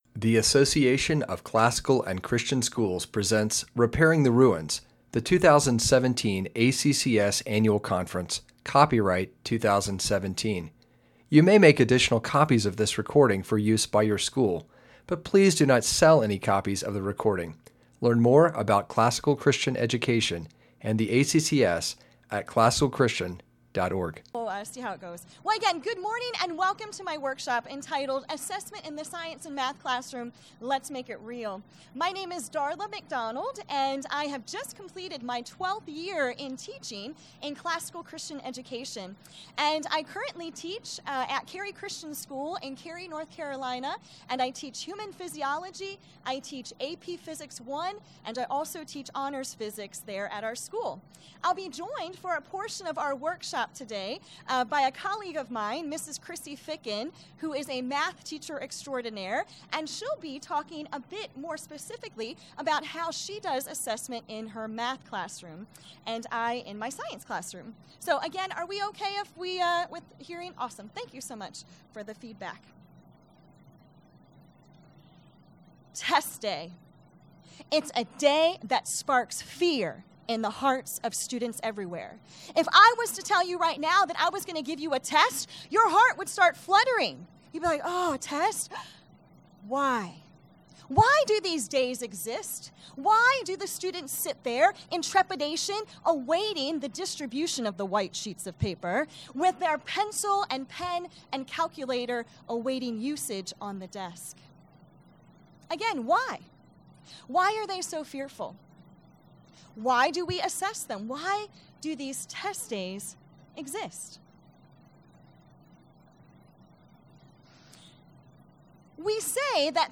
2017 Workshop Talk | 1:02:22 | All Grade Levels, Math, Science